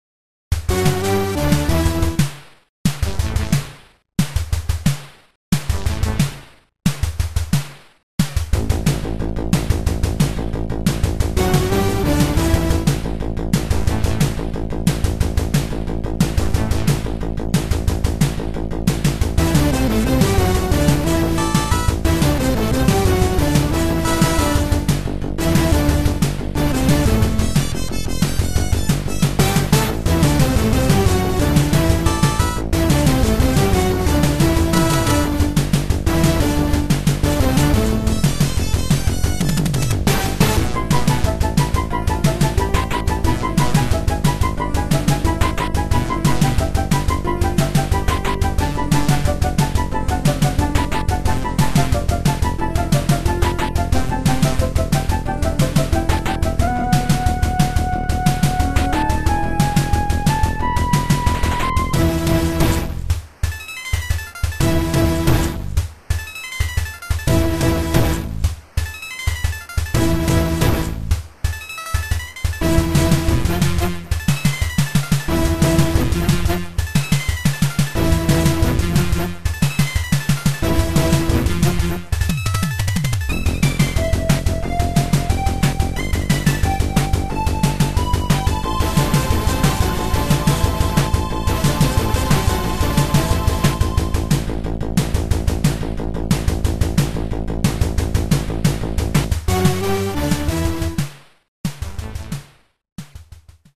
PSG